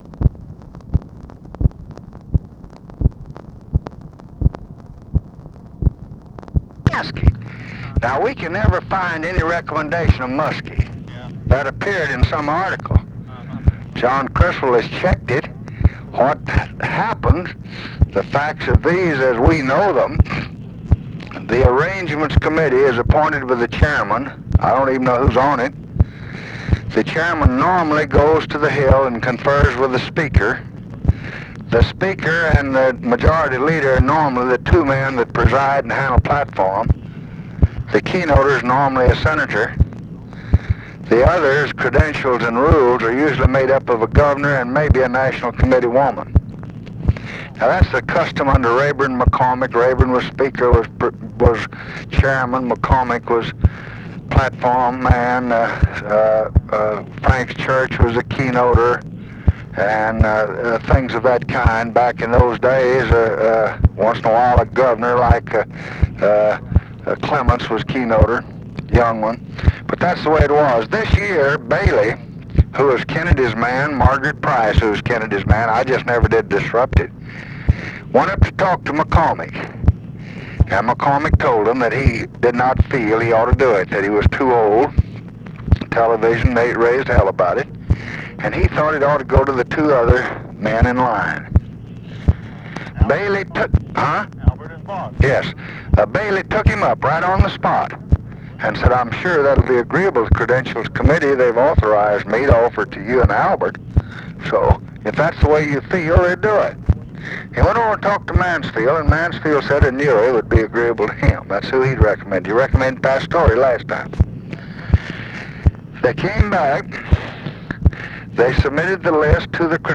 Conversation with LEONARD MARKS, September 2, 1968
Secret White House Tapes